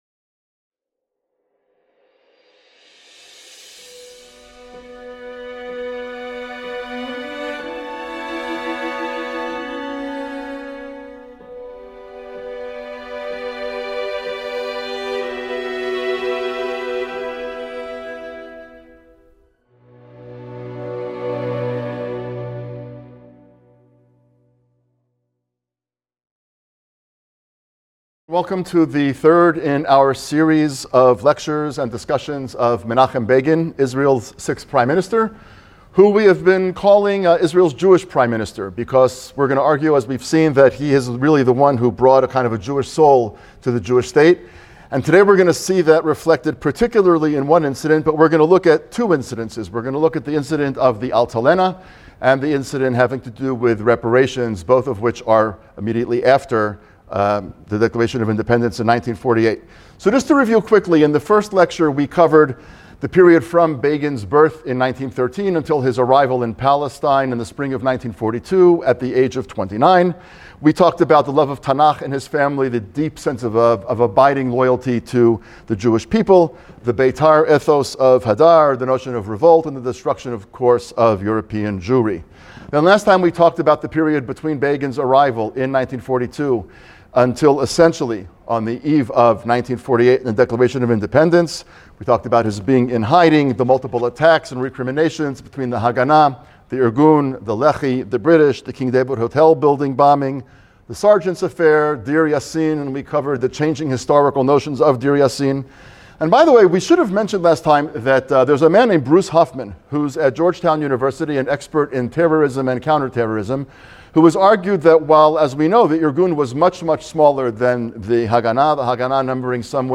In this lecture